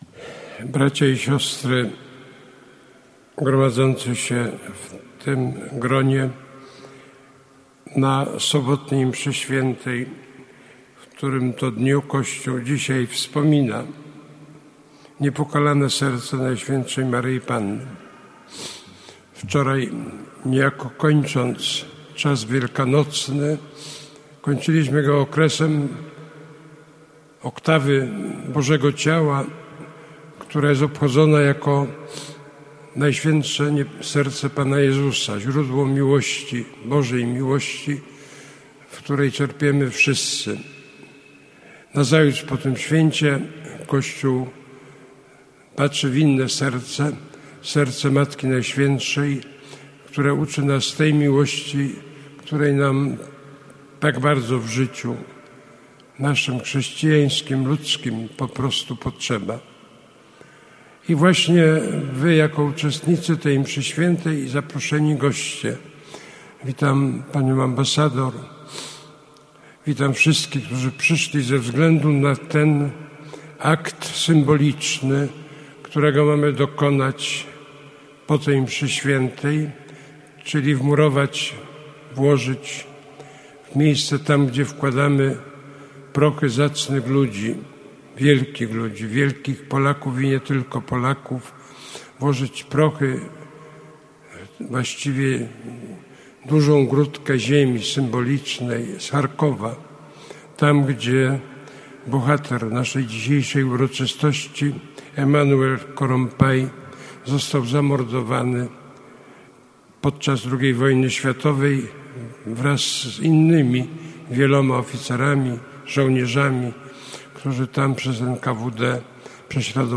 W sobotę, 25 czerwca, gdy Kościół na całym świecie obchodzi liturgiczne wspomnienie Niepokalanego Serca Najświętszej Maryi Panny, w Świątyni Opatrzności Bożej odbyło się symboliczne upamiętnienie kapitana Emánuela Korompayego, Węgra, który przyjął Polskę za swą drugą ojczyznę.
Kardynal-Kazimierz-Nycz-podczas-symbolicznego-pogrzebu-kpt.-Emanuela-Korompay-Kopia.mp3